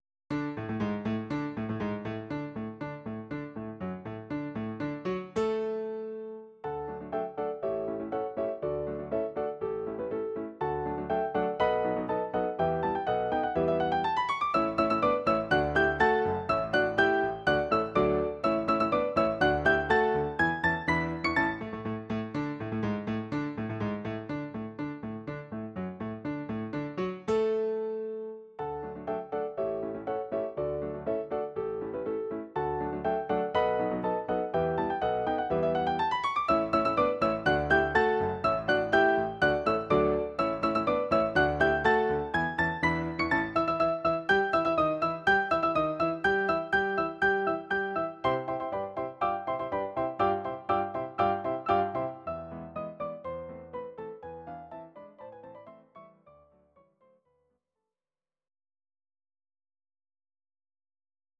These are MP3 versions of our MIDI file catalogue.
Your-Mix: Instrumental (2074)